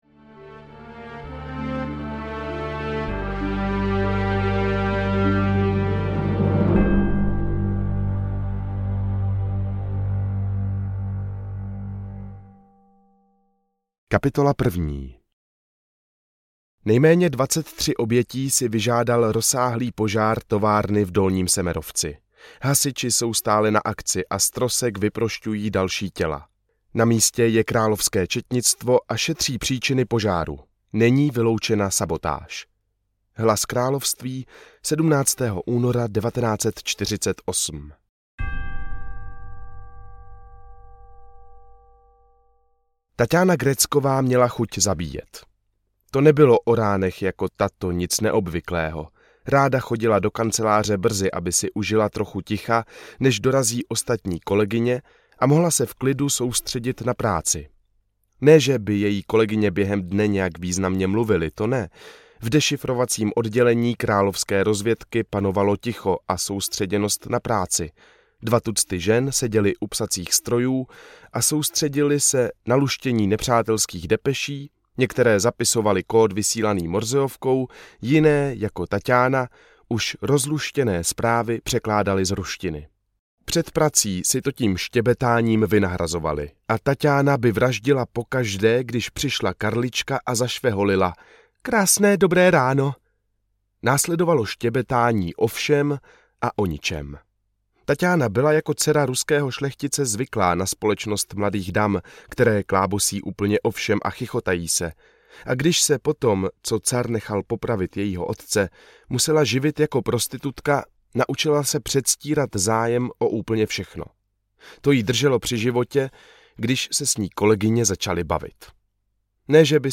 V tajné službě Koruny české audiokniha
Ukázka z knihy